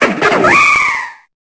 Cri de Darumarond dans Pokémon Épée et Bouclier.